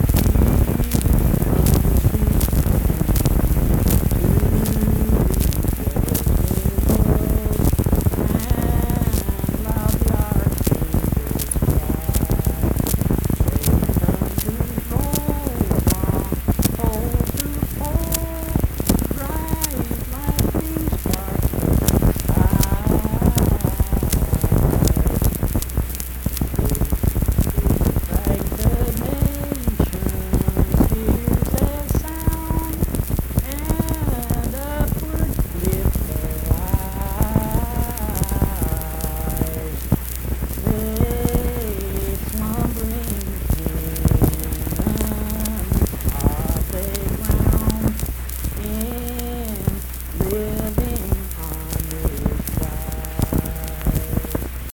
Accompanied (guitar) and unaccompanied vocal music
Performed in Mount Harmony, Marion County, WV.
Hymns and Spiritual Music
Voice (sung)